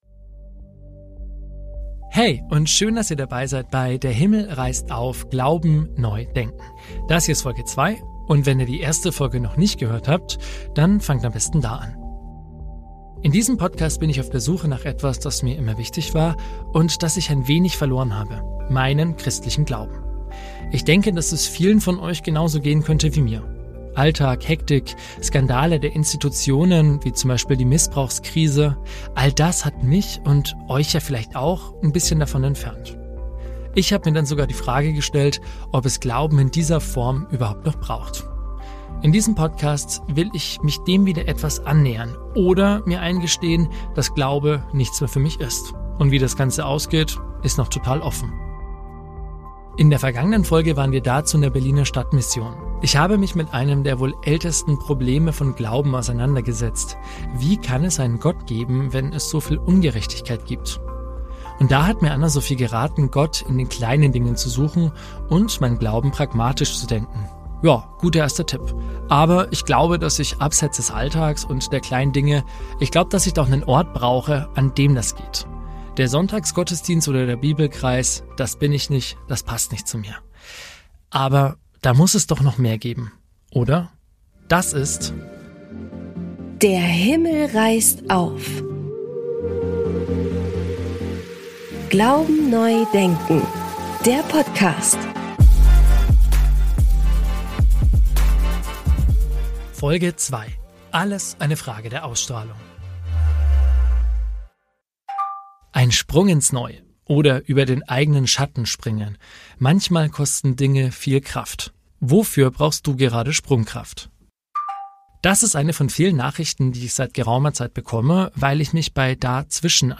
Und so kam es zu einem ziemlich herausfordernden Glaubensgespräch …